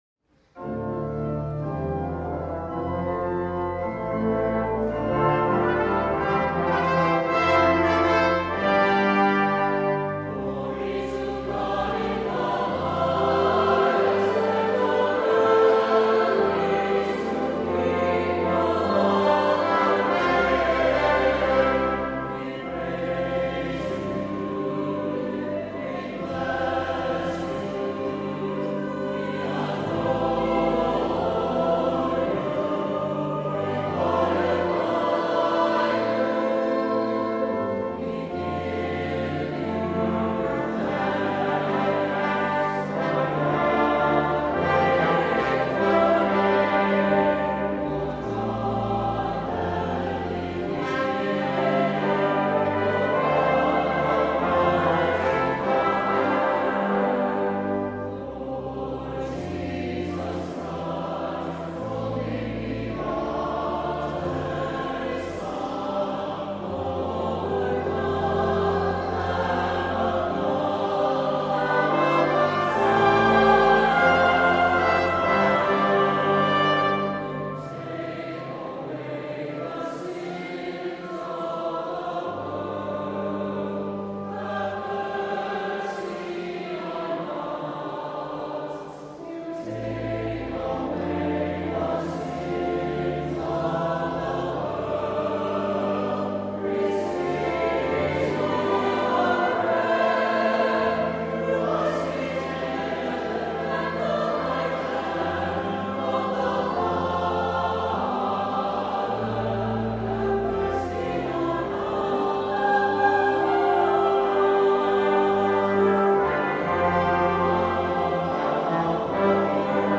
Voicing: "SATB","Cantor","Priest","Assembly"